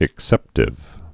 (ĭk-sĕptĭv)